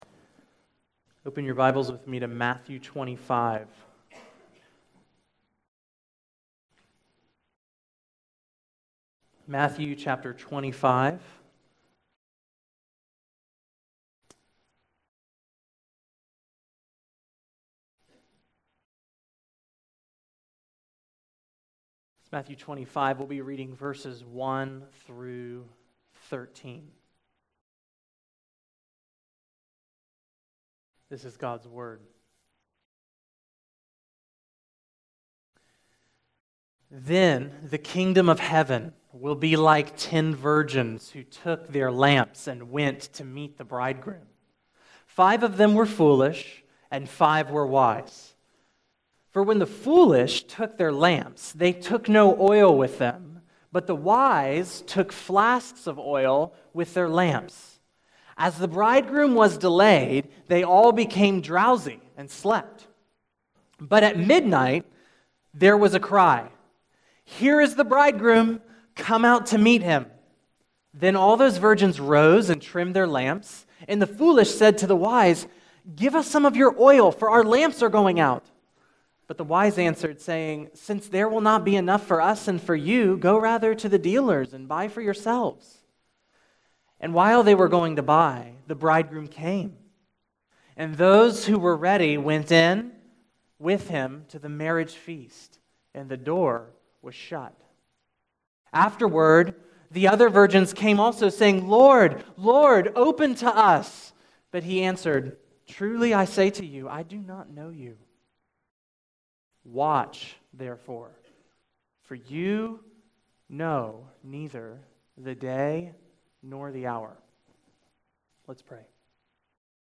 July 2, 2017 Morning Worship | Vine Street Baptist Church